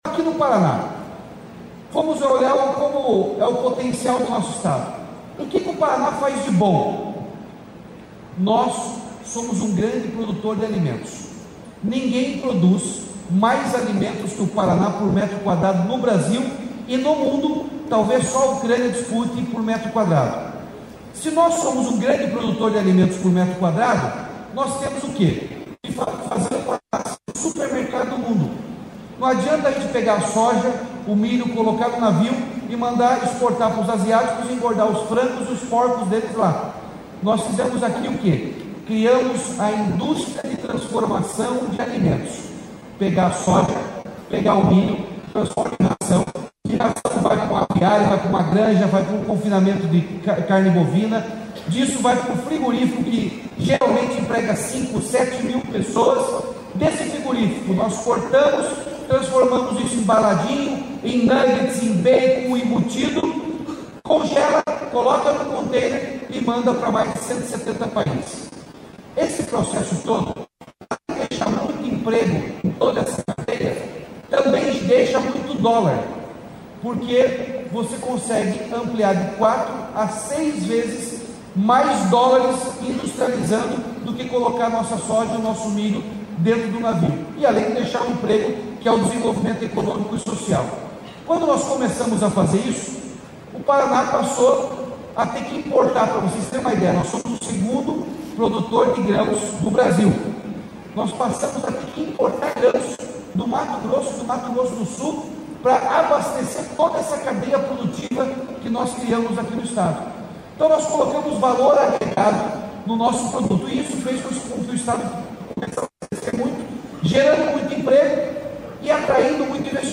Sonora do governador Ratinho Junior na Varejo Experience Brasil